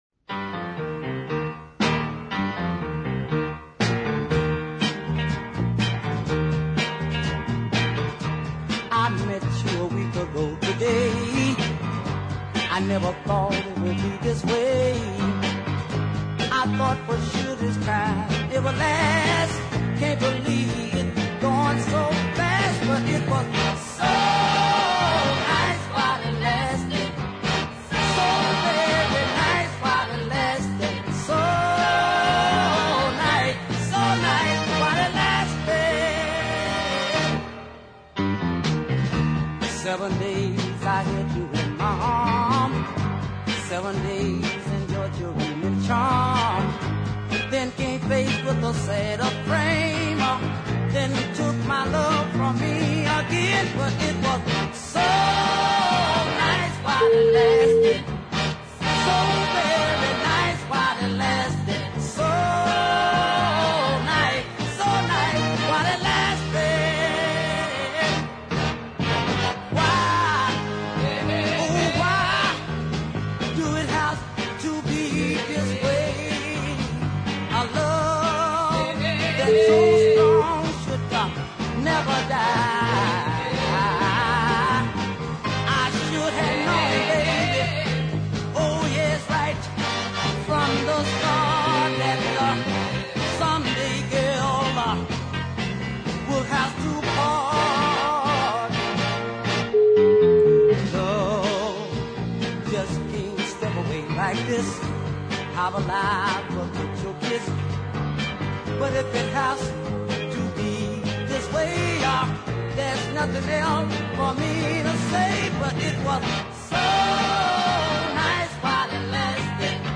mid paced